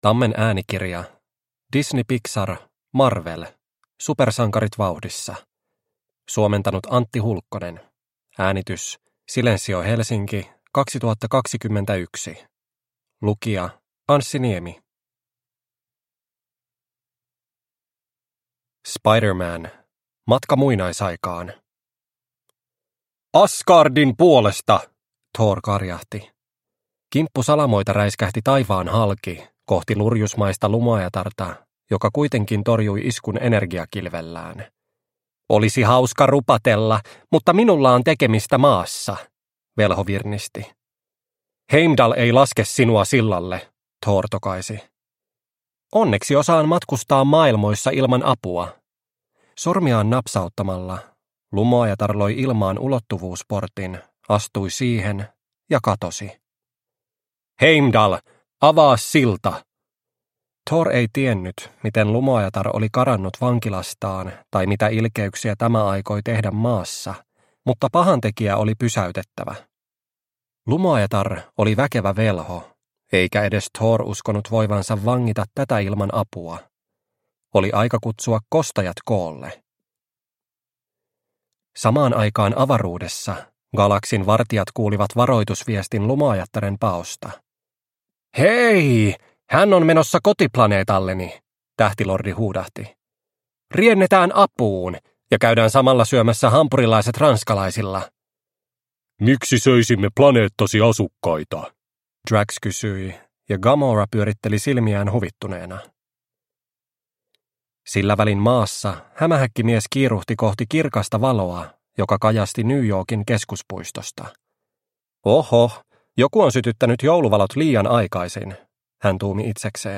Marvel. Supersankarit vauhdissa – Ljudbok – Laddas ner